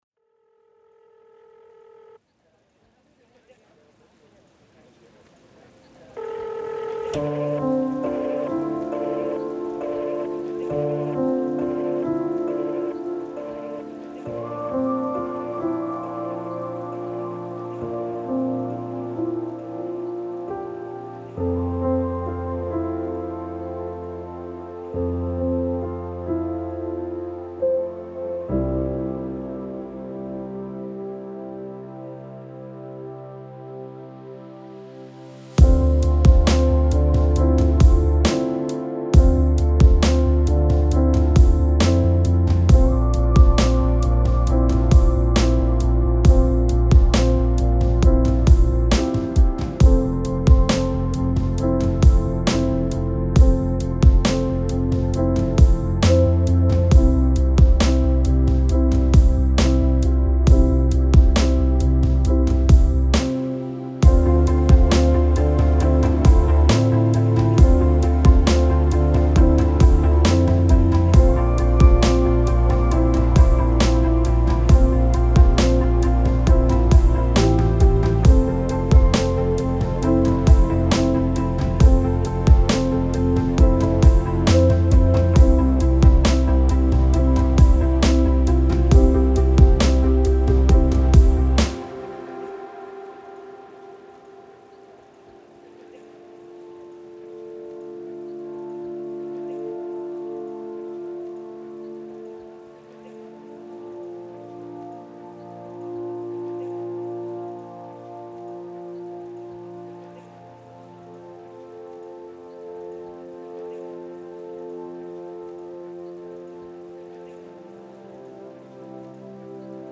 это проникновенная кантри-песня